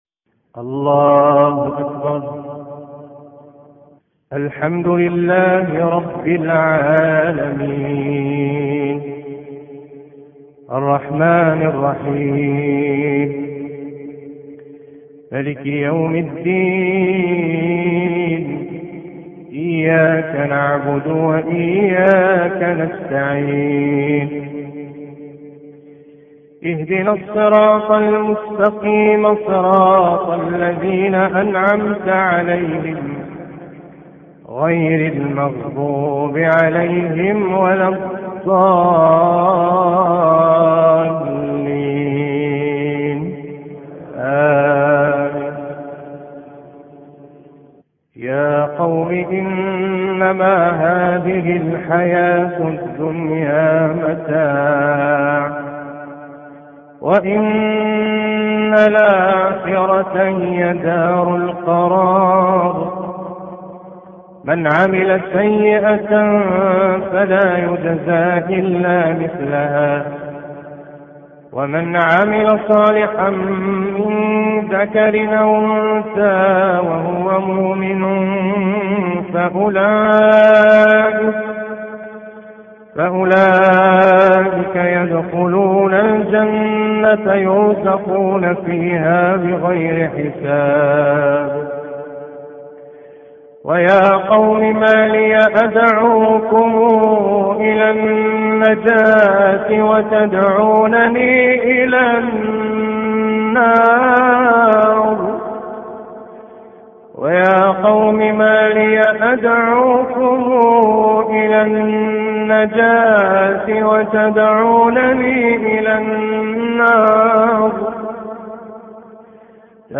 تلاوة